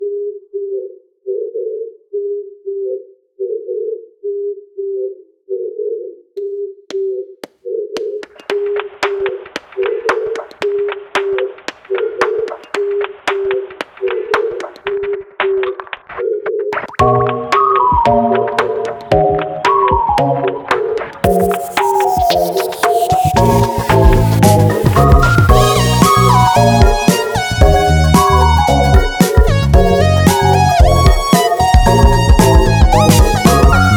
リズムを見出されたハトの鳴き声
別にドラムやシンセのような楽器音が無かったとしても、「ホーホー、ホホー」自体がすでに音楽的なリズム素材です。